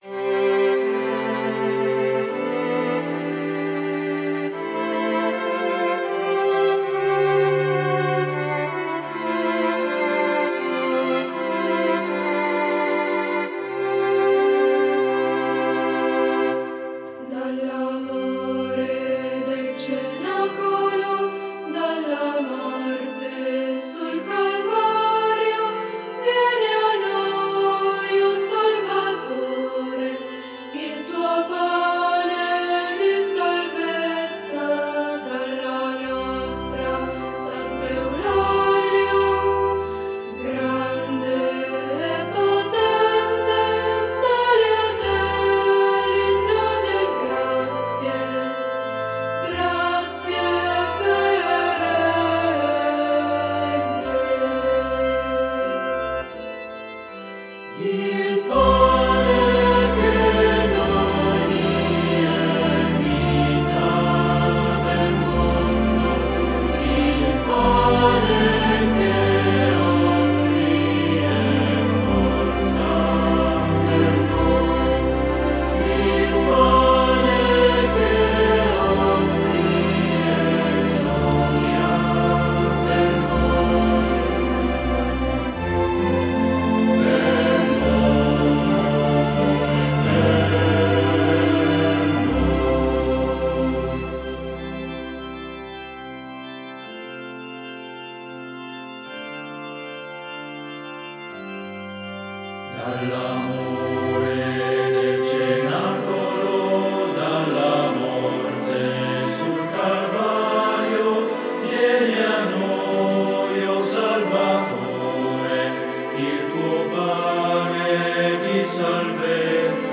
Coro
Esattamente per questi motivi, abbiamo registrato voci e musiche di alcuni di questi canti, trascrivendone gli spartiti, perché restino a disposizione di chi vorrà lodare e ringraziare il Signore e la Sua dolcissima Madre.